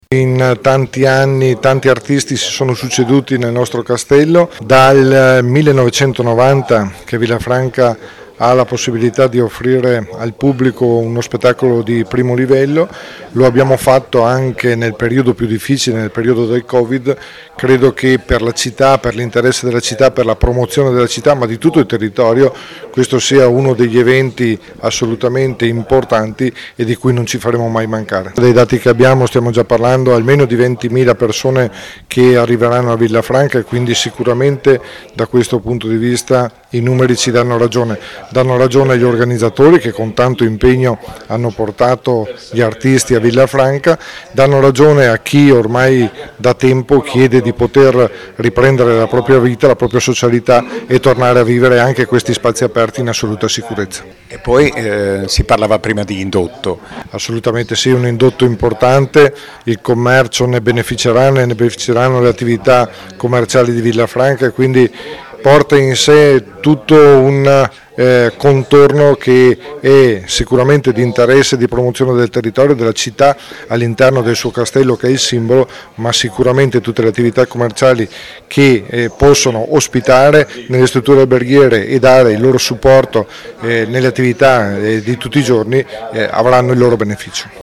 Roberto Dall’Oca, sindaco di Villafranca
Villafranca-Festival-Roberto-DallOca-sindaco-di-Villafranca.mp3